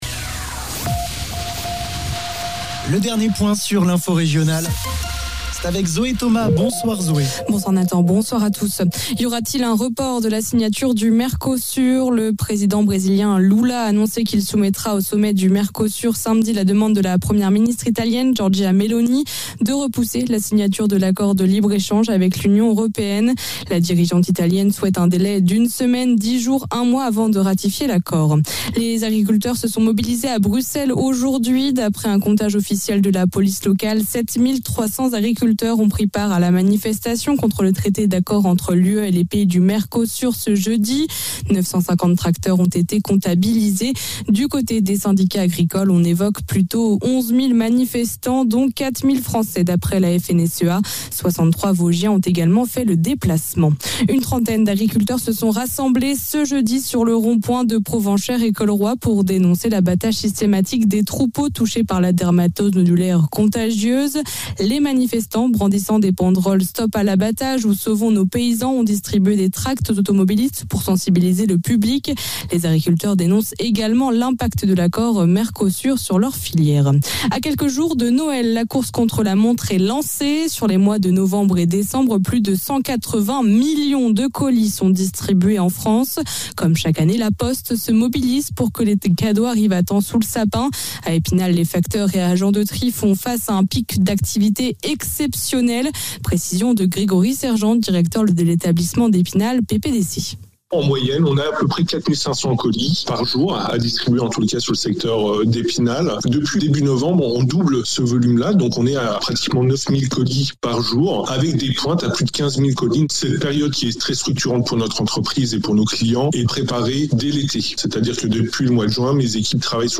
Le flash de 19h